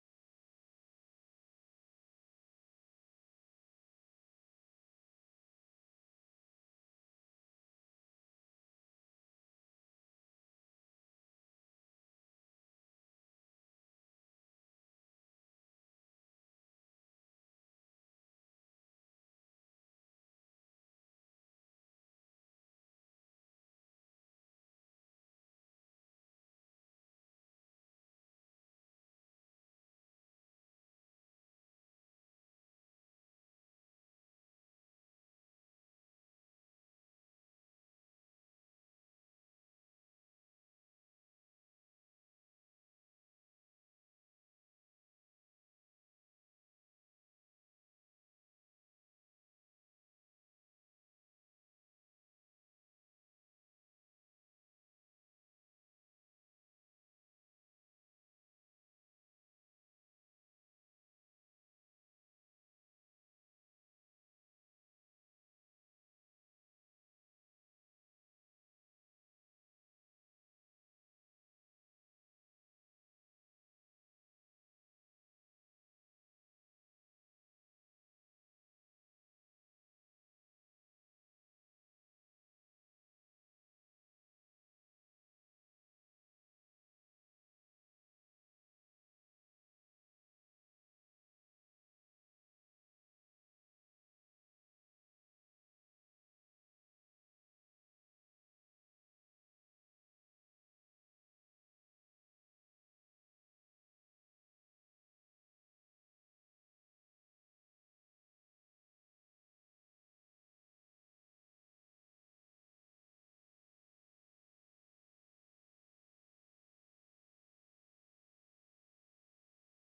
FWC-Easter-2025-Praise-and-Worship-Audio-CD.mp3